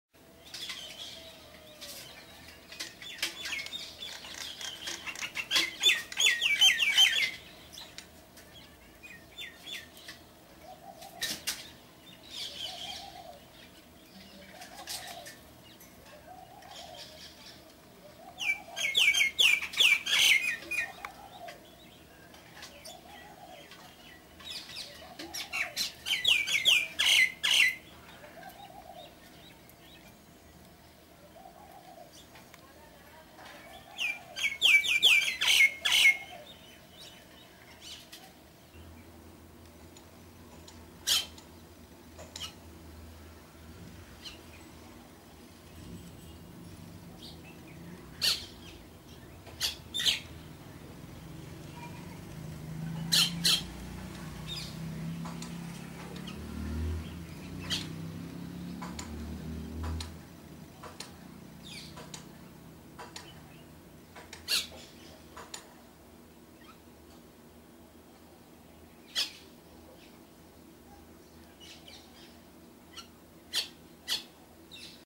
دانلود صدای مرغ مینا از ساعد نیوز با لینک مستقیم و کیفیت بالا
جلوه های صوتی